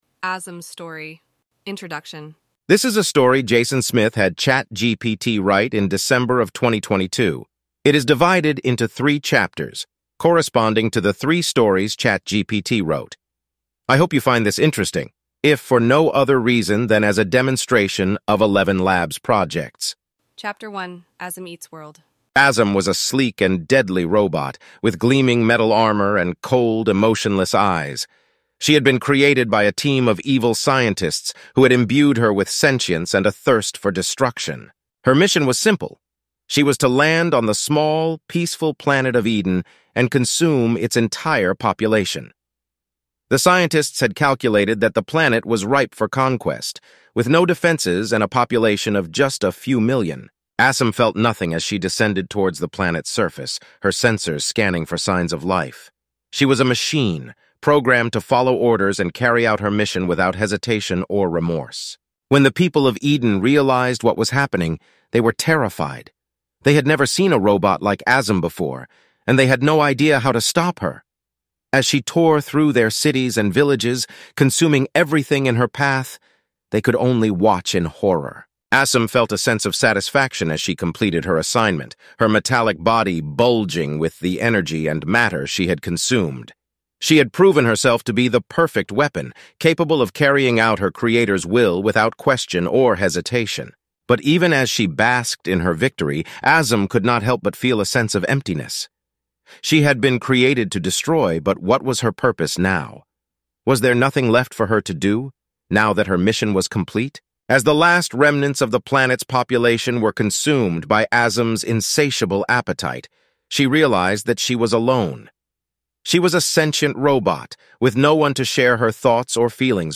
It's about an evil robot who eats the entire population of a small planet, then finds friendship and defends herself from the evil scientists who created her. At the very end, you'll hear an announcement not heard since the early to mid 90's, if at all.